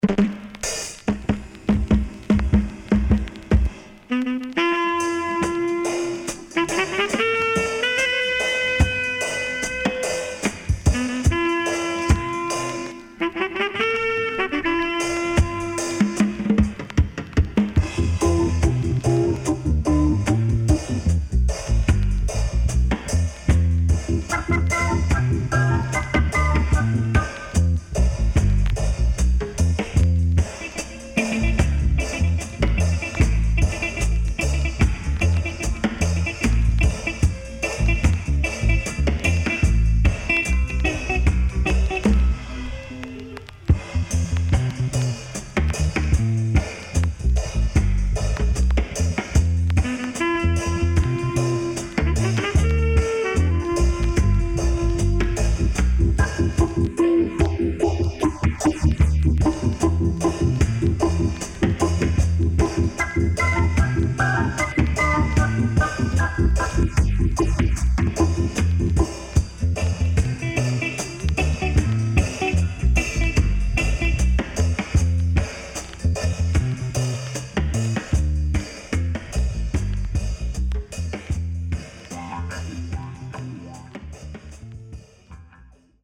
SIDE A:所々チリノイズがあり、少しプチノイズ入ります。